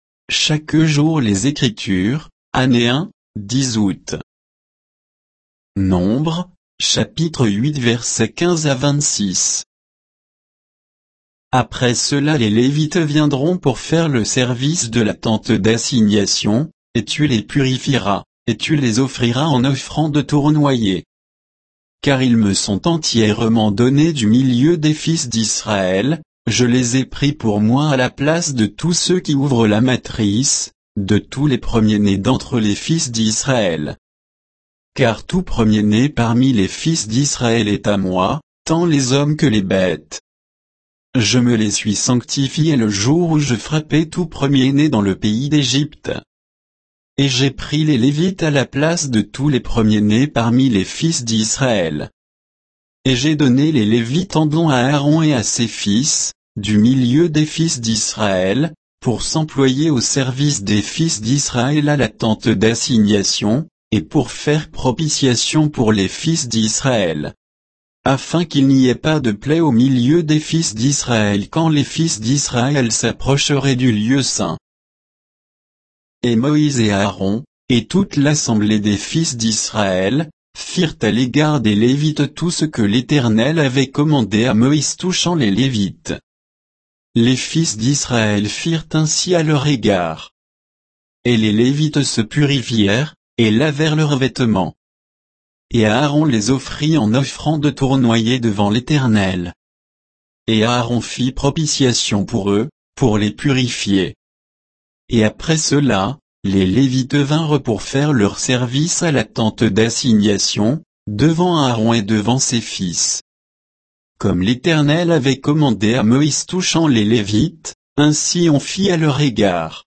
Méditation quoditienne de Chaque jour les Écritures sur Nombres 8, 15 à 26